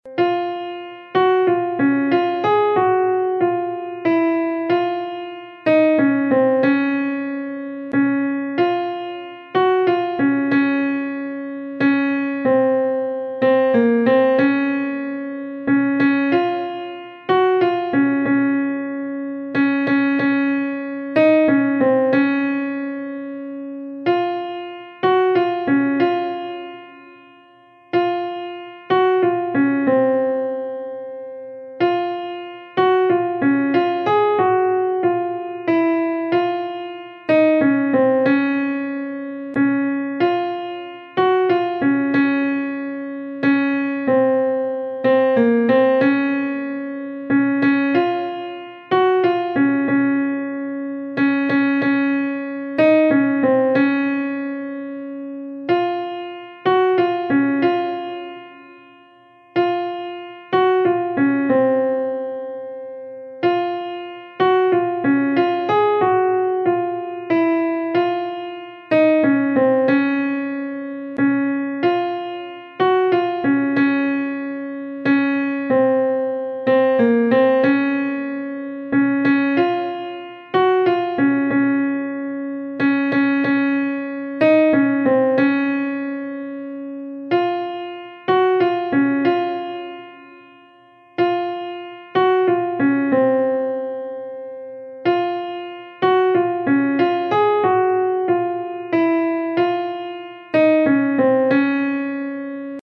伴奏
女高 下载